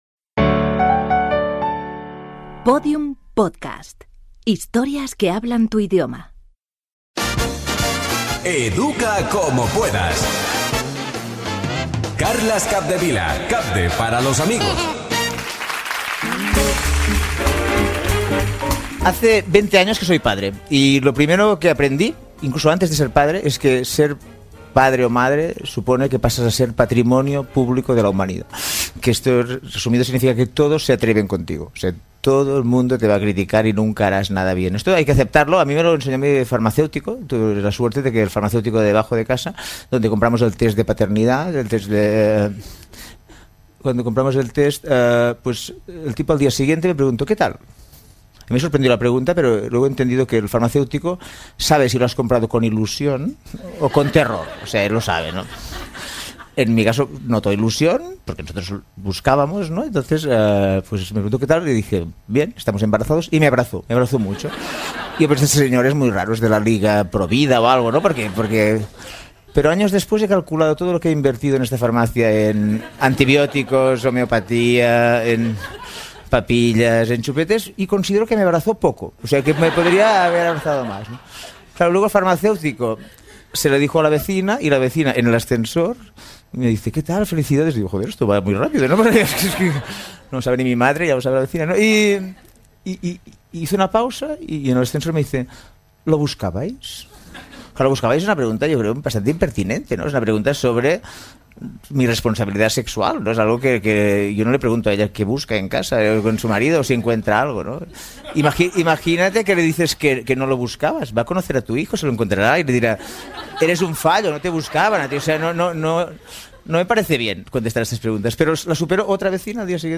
Identificació de la plataforma, careta del programa i primer episodi dedicat a un monòleg humorístic basat en les frases clàssiques dels pares
Entreteniment